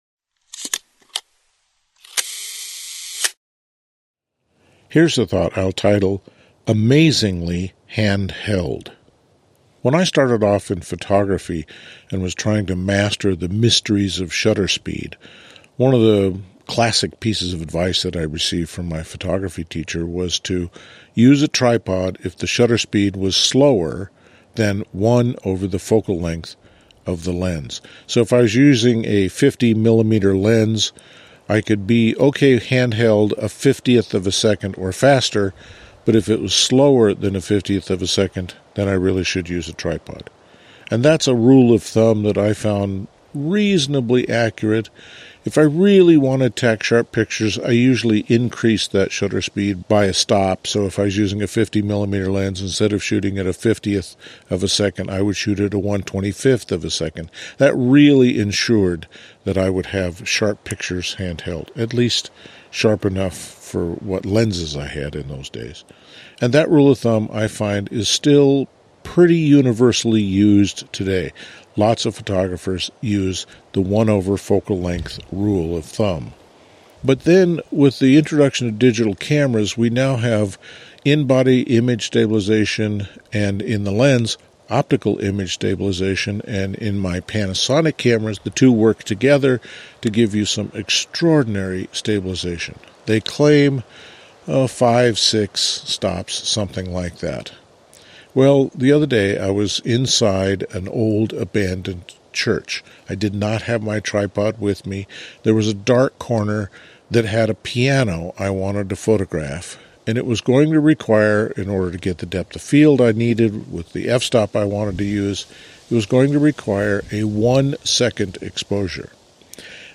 These talks focus on the creative process in fine art photography.